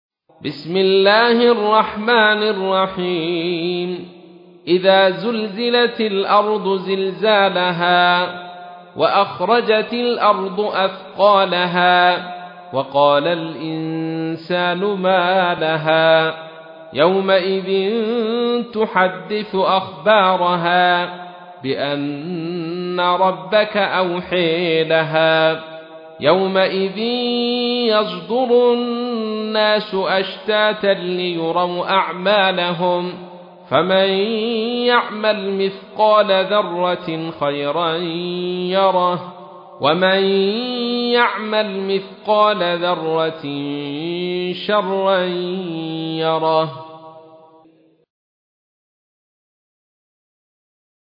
تحميل : 99. سورة الزلزلة / القارئ عبد الرشيد صوفي / القرآن الكريم / موقع يا حسين